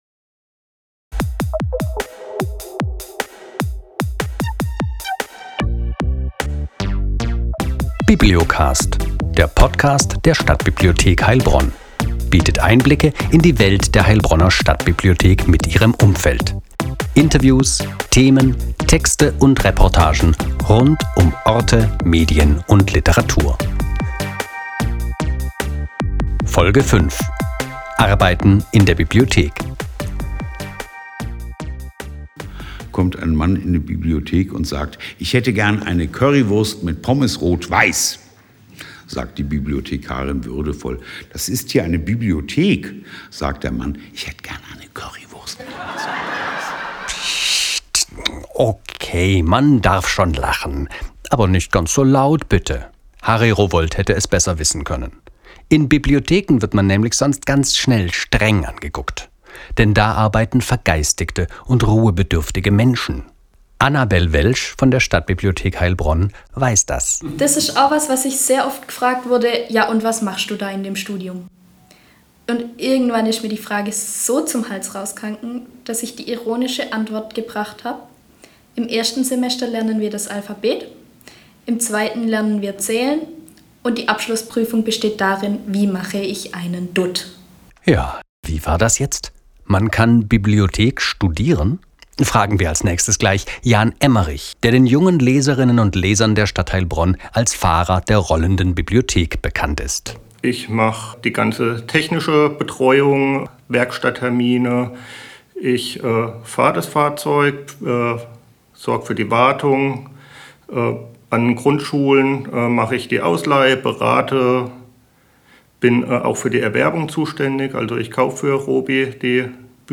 Die Bibliothek als Arbeitsplatz: kein verstaubter Ort der Stille, sondern ein vielseitiges und modernes Instrument der Stadtgesellschaft. Interviews